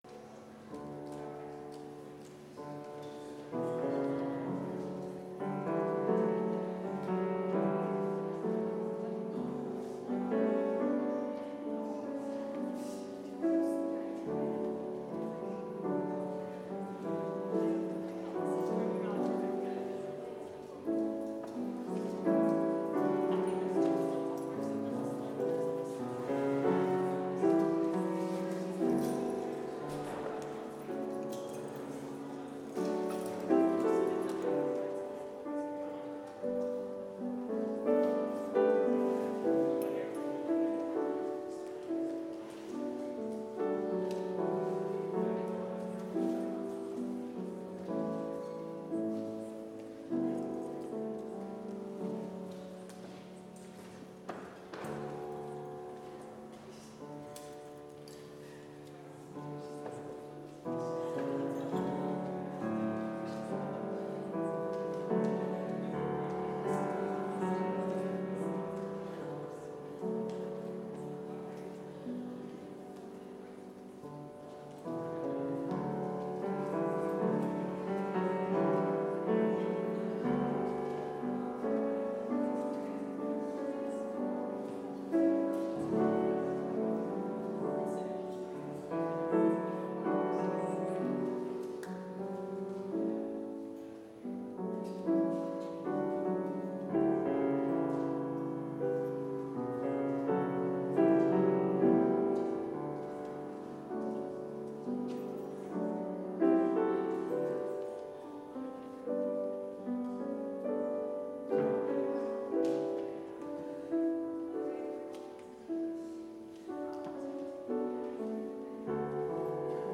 Complete service audio for Chapel - January 15, 2021